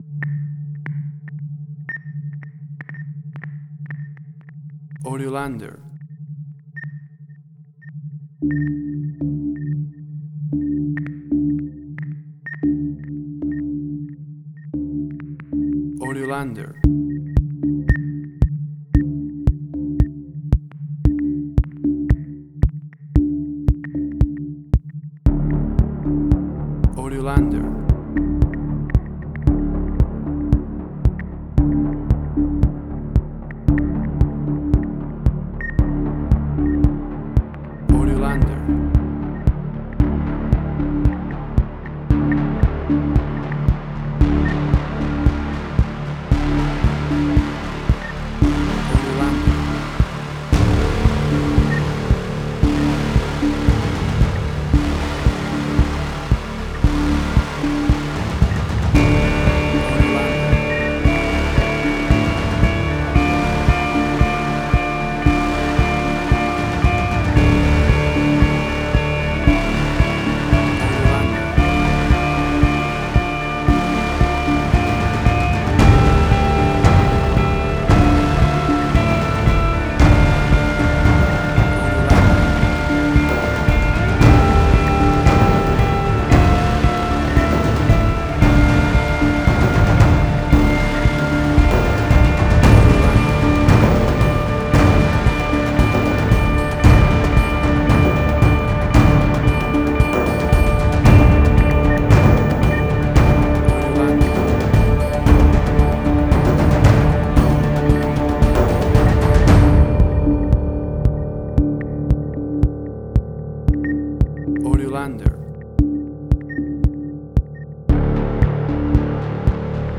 Modern Science Fiction Film, Similar Tron, Legacy Oblivion.
WAV Sample Rate: 16-Bit stereo, 44.1 kHz
Tempo (BPM): 114